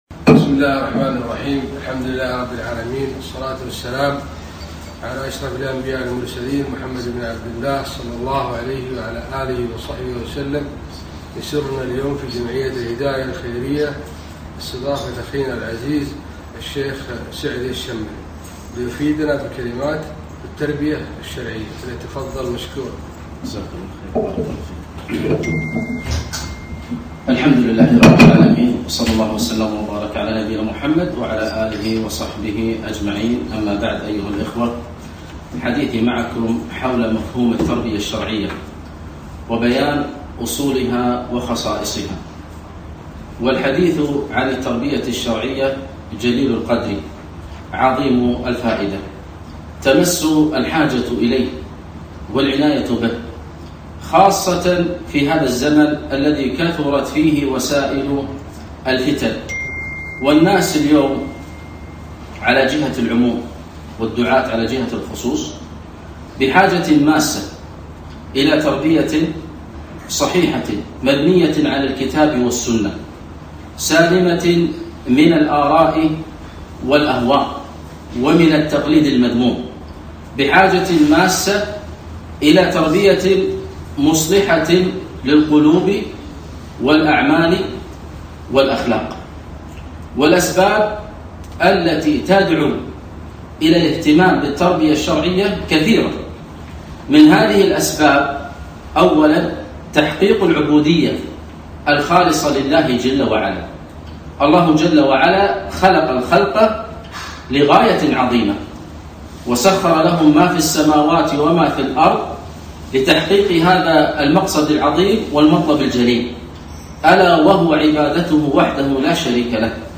محاضرة - التربية الشرعية مفهومها وأصولها وخصائصها